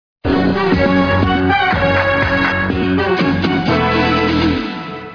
commercial break music